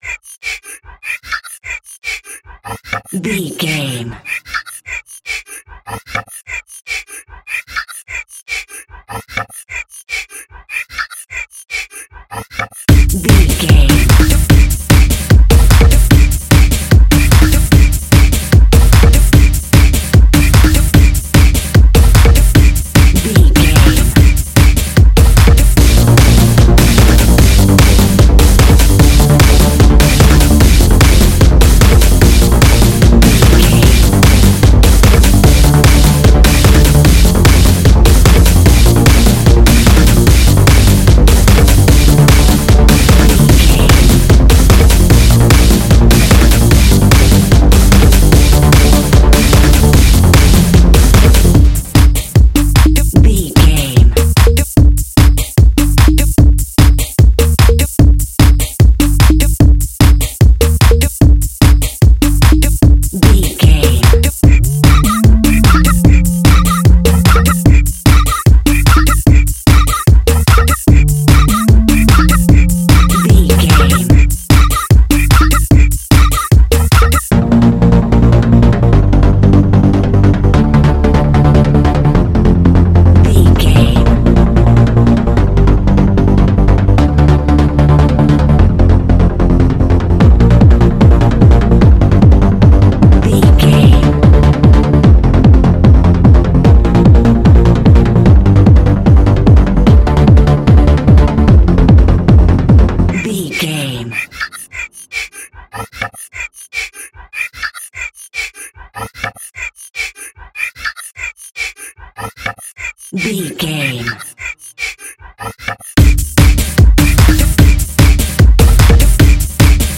Aeolian/Minor
Fast
futuristic
heavy
energetic
uplifting
hypnotic
industrial
drum machine
synthesiser
percussion
acid house
electronic
uptempo
synth leads
synth bass